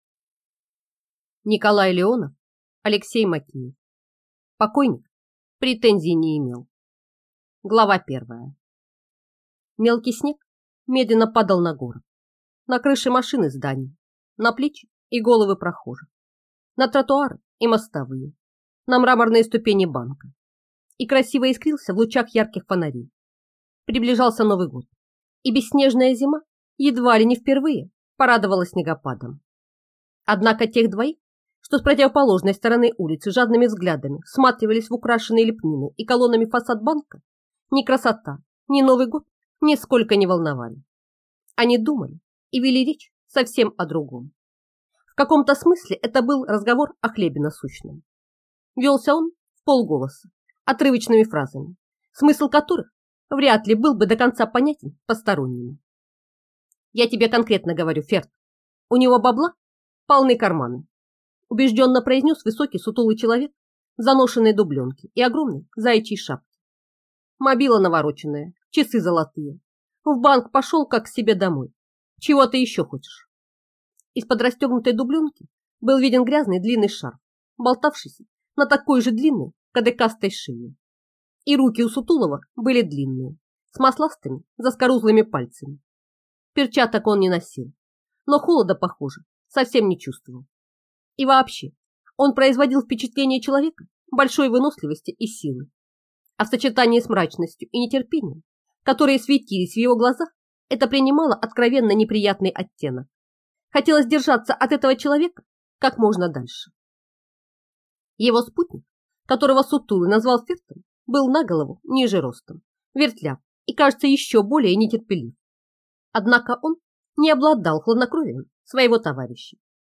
Аудиокнига Покойник претензий не имел | Библиотека аудиокниг